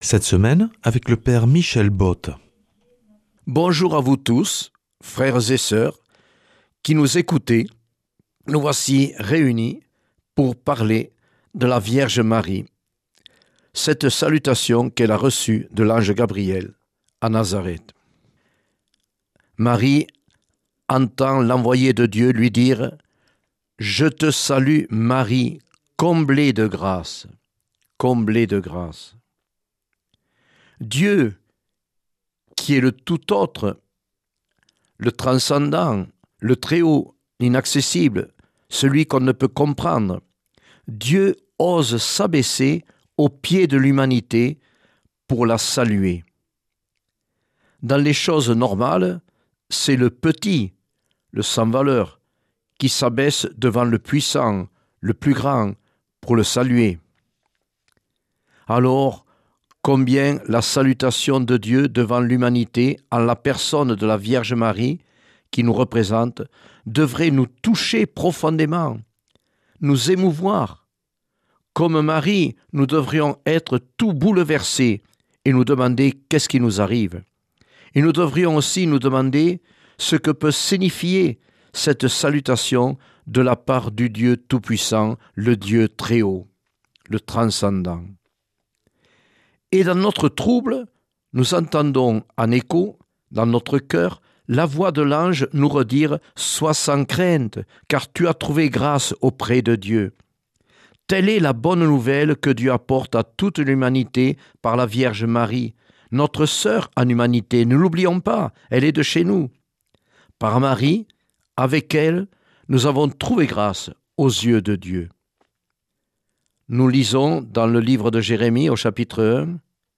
mardi 7 avril 2026 Enseignement Marial Durée 10 min